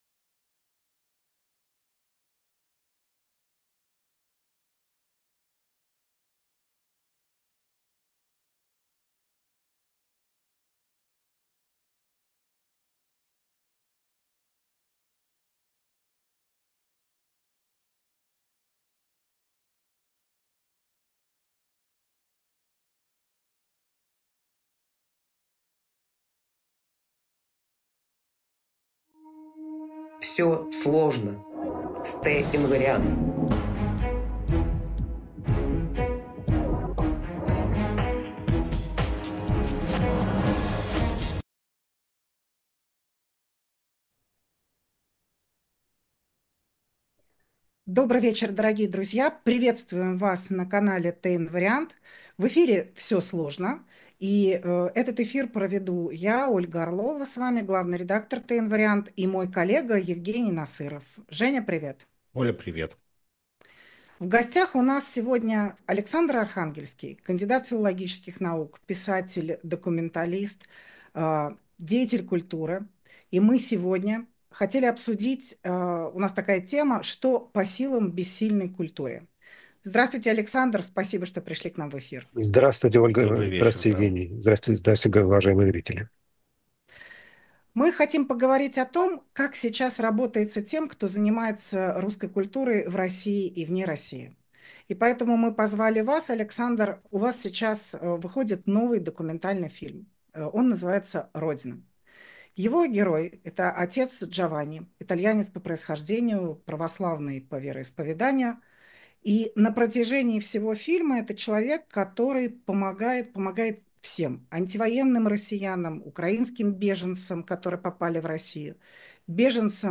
У нас в гостях литературовед, публицист, писатель Александр Архангельский . Мы обсудили новый фильм Александра Николаевича, премьера которого состоится в ближайшее время, новую книгу писателя, а также изменение культуры, в том числе повседневной, в России, и воззвания священнослужителей и мирян РПЦ, остающихся в России и не принявших войну.